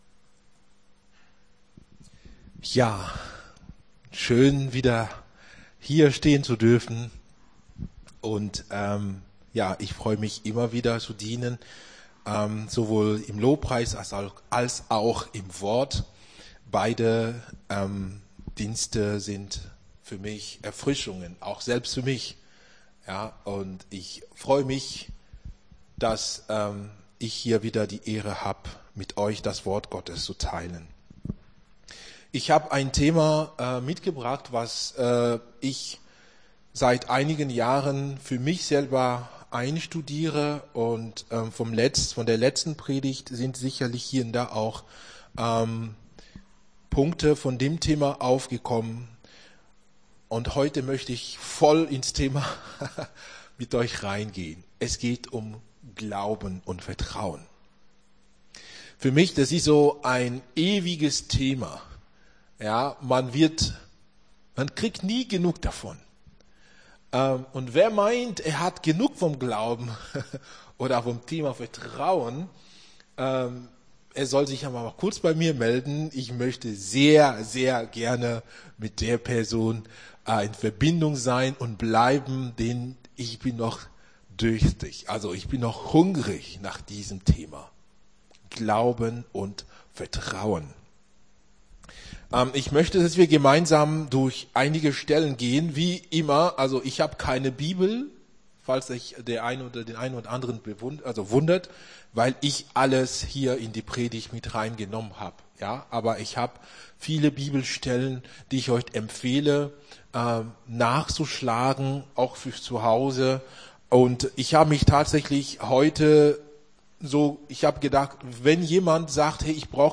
Gottesdienst 16.07.23 - FCG Hagen